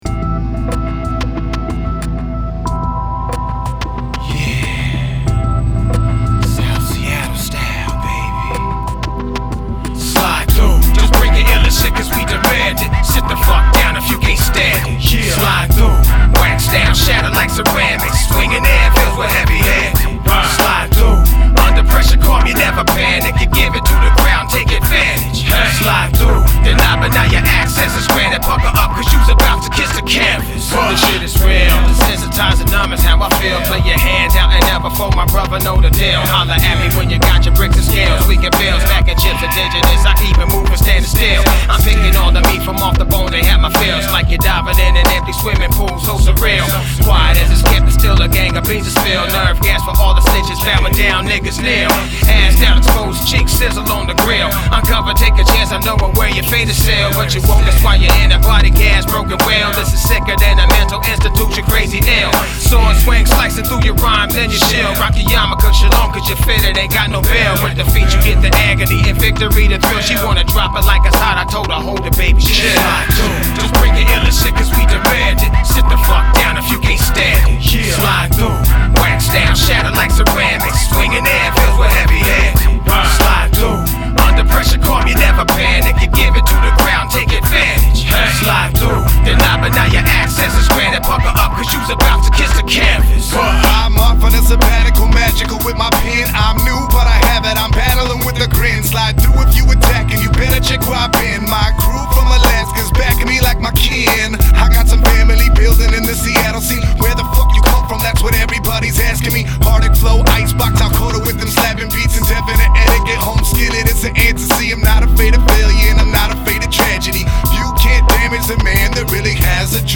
hip hop
Recorded at Ground Zero Studios